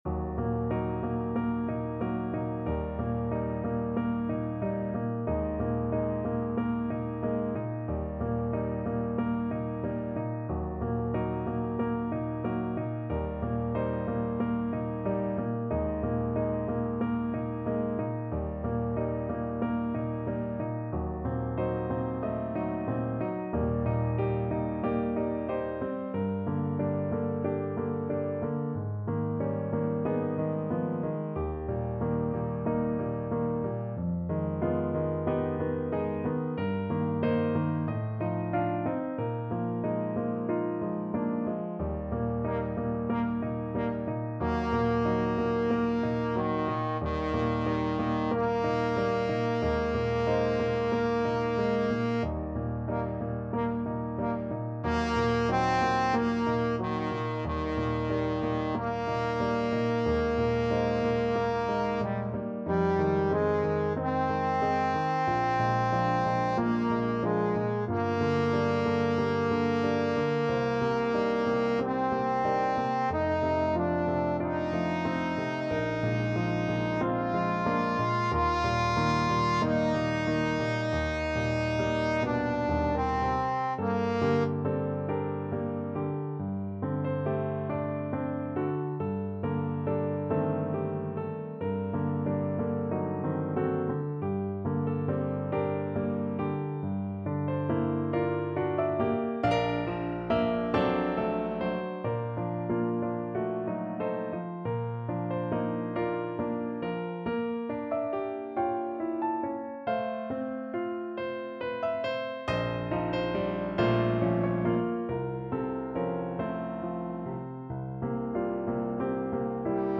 Classical Rachmaninoff, Sergei Cello Sonata, Op. 19, Slow Movement Trombone version
Trombone
Eb major (Sounding Pitch) (View more Eb major Music for Trombone )
4/4 (View more 4/4 Music)
Andante (=46)
Classical (View more Classical Trombone Music)